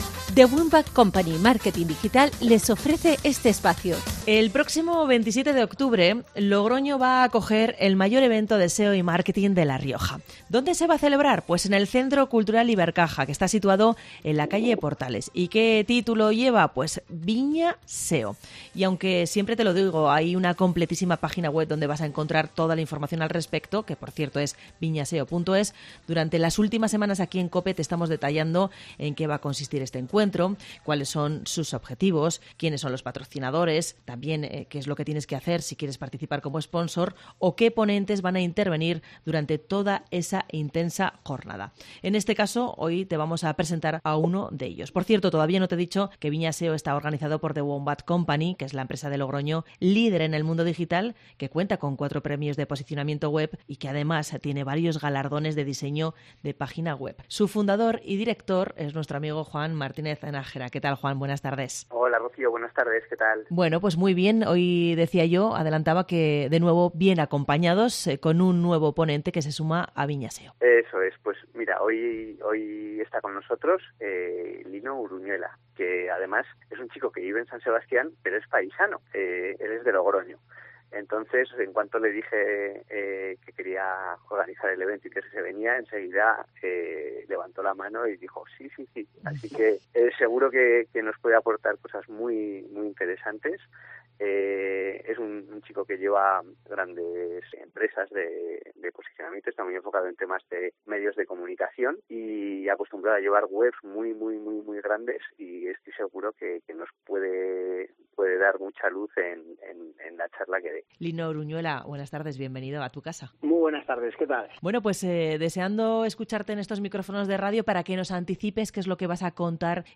Hoy ha pasado por los micrófonos de COPE Rioja para avanzarnos en qué centrará su exposición: